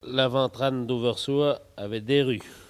Catégorie Locution